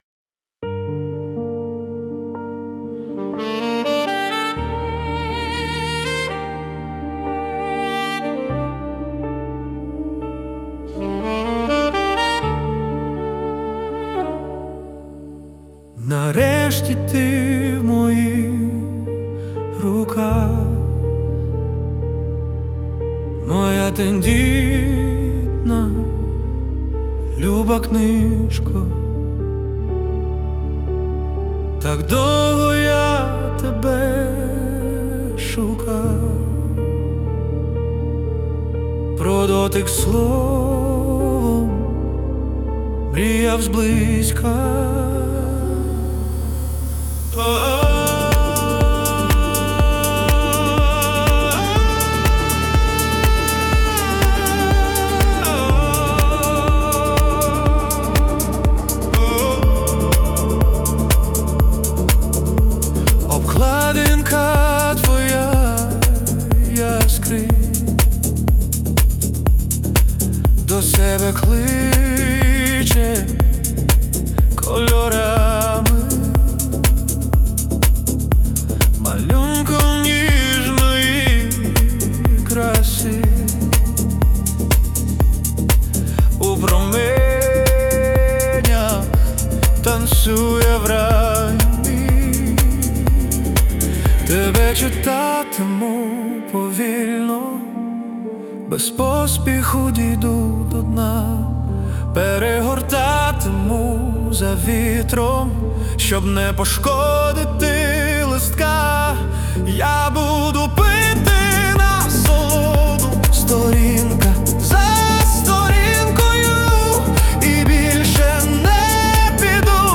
Музика і вокал ШІ - SUNO AI v4.5+
СТИЛЬОВІ ЖАНРИ: Ліричний
ВИД ТВОРУ: Пісня